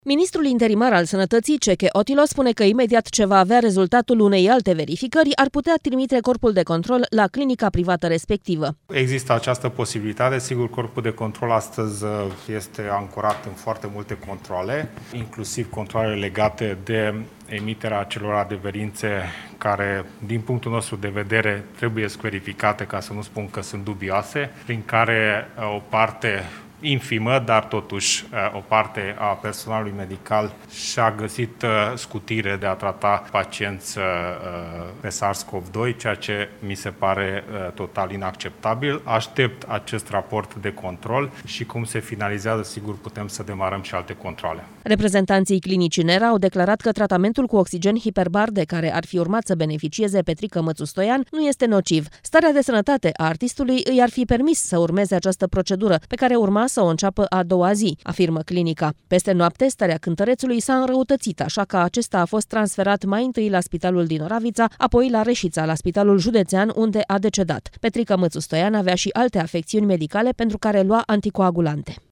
Ministrul interimar al Sănătății, Cseke Attila, spune că imediat ce va avea rezultatul unei alte verificări, ar putea trimite corpul de control la clinica privată respectivă: